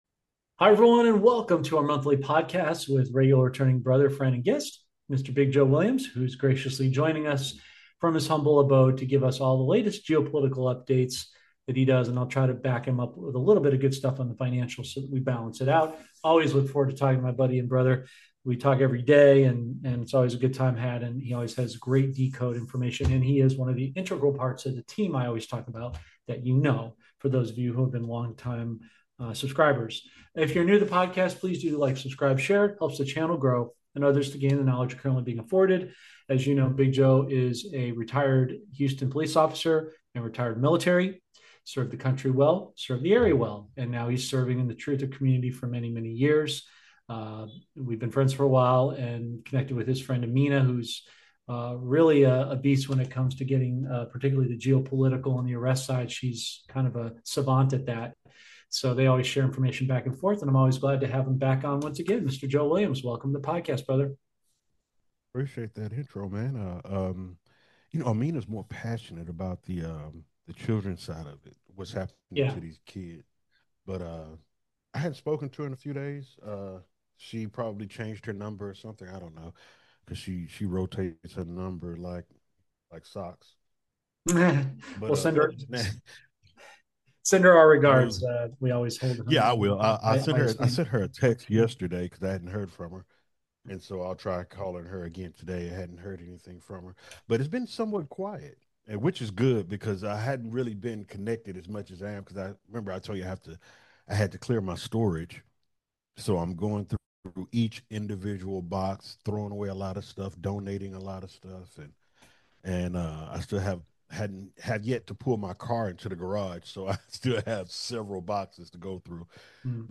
The hosts discuss various topics, including their personal lives, geopolitical issues, and financial matters. ➡ The text discusses allegations that the Obama administration manipulated intelligence to suggest Russian interference in the 2016 election.